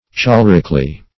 cholericly - definition of cholericly - synonyms, pronunciation, spelling from Free Dictionary Search Result for " cholericly" : The Collaborative International Dictionary of English v.0.48: Cholericly \Chol"er*ic*ly\, adv.
cholericly.mp3